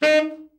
TENOR SN  20.wav